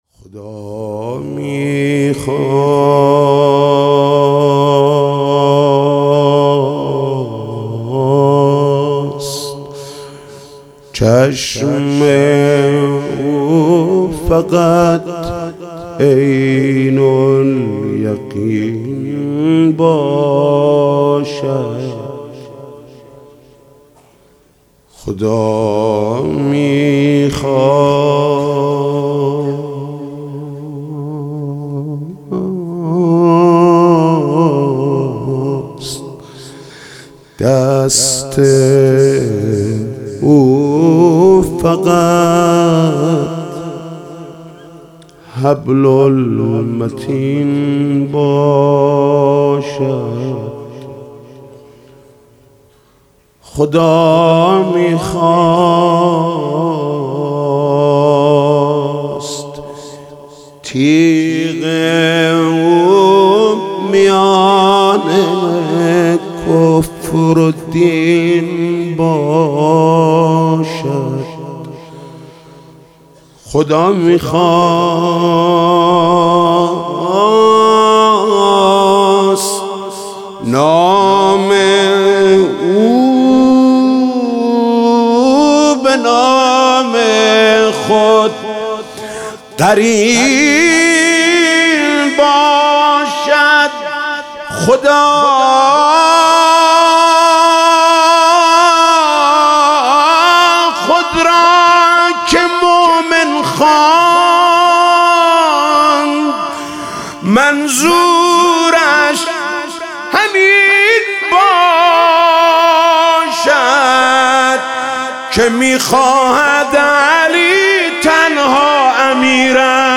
مدح: خدا میخواست چشم او فقط عین الیقین باشد